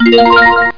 CHIME.mp3